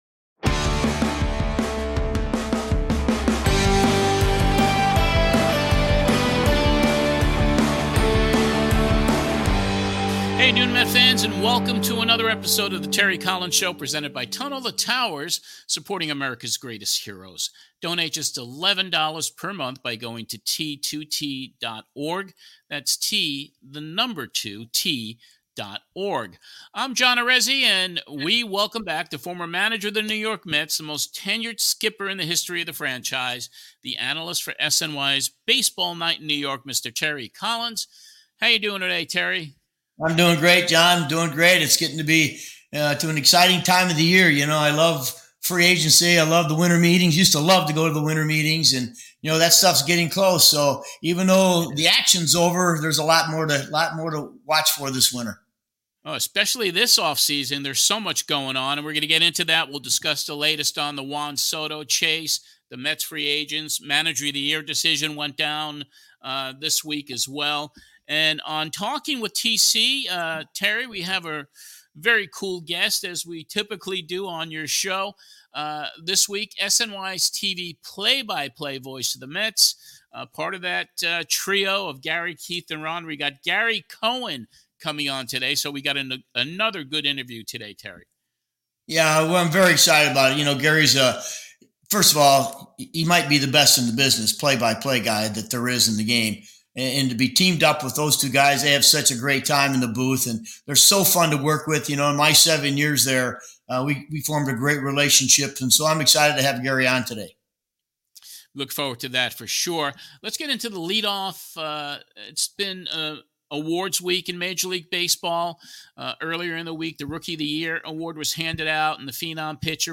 In the 2nd to last show of the 2024 Season, Terry Collins welcomes the TV play by play voice of the NY Mets - Gary Cohen to the show. Gary discusses the 2024 season and how magical it was.
Plus he answers fans questions as well.